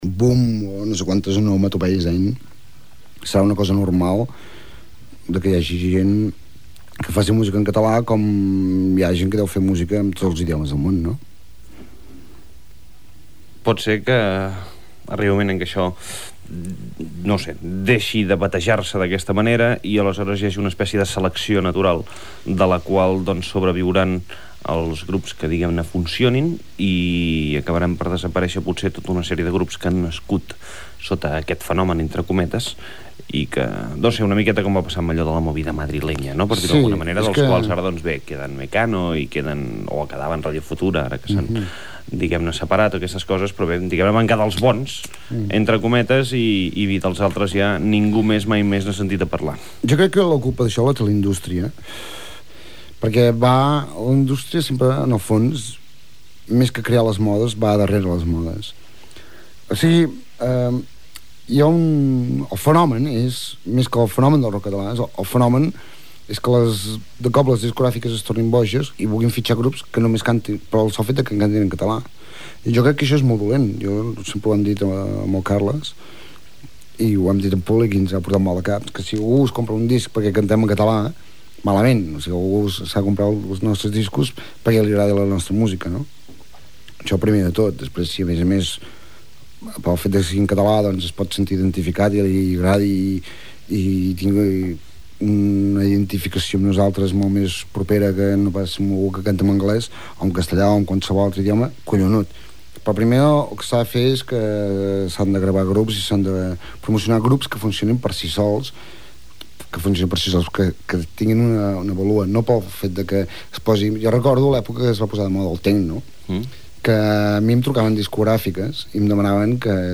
Entrevista a Pep Sala, component del grup Sau.